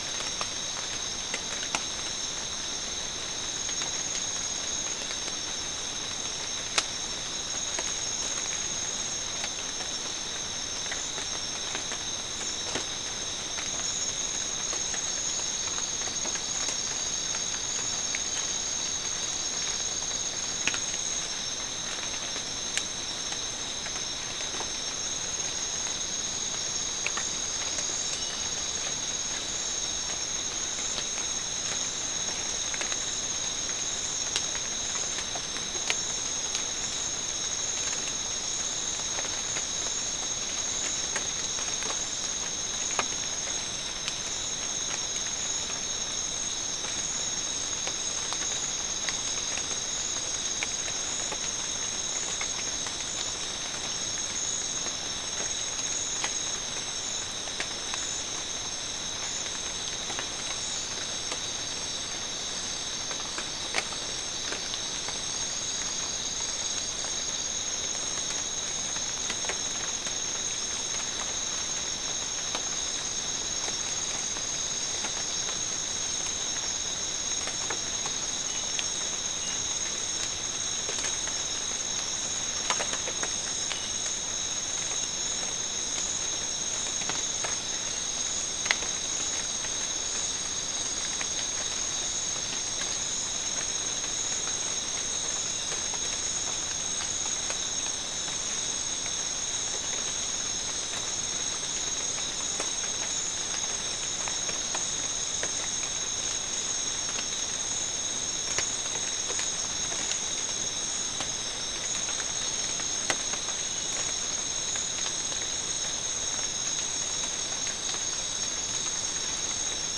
Soundscape
South America: Guyana: Sandstone: 1
Recorder: SM3